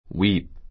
wíːp ウィ ー プ
wépt ウェ プト